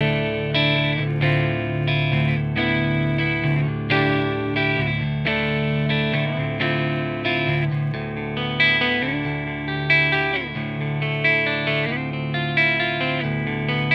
The detail comes up a lot with the mod as does the bandwidth
For Vocals a Rode Ntk was used. For guitar an Oktave Ml52 Ribbon mic with a 10K boost on the preamp set to 3 o'clock
Revive Audio Modified WA73-EQ On Clean Guitar